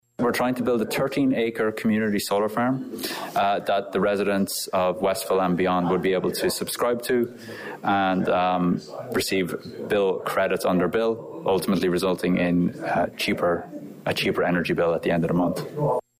(Representatives from Solar Provider Group speak to citizens at Westville’s Public Library Thursday evening,  November 2, 2023.)